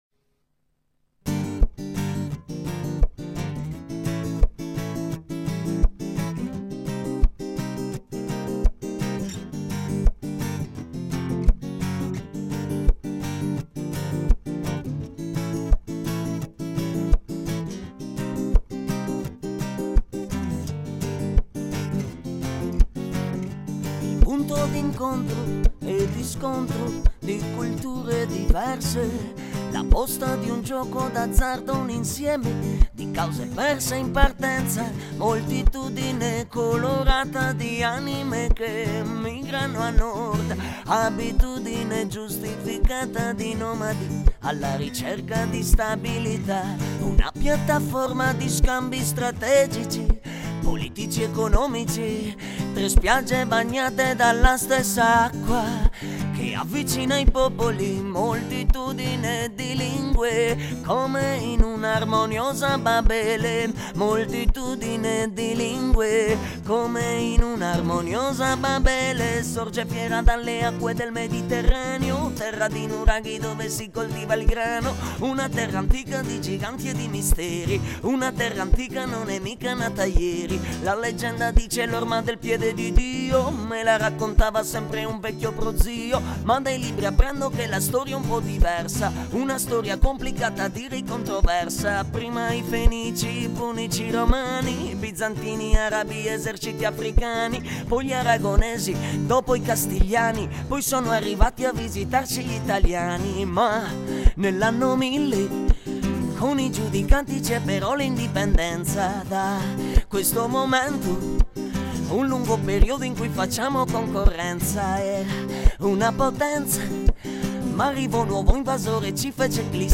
Mediterraneo è la ballata che ho scritto appositamente per il sito e che, di frequente, eseguo dal vivo, in compagnia di amici e conoscenti.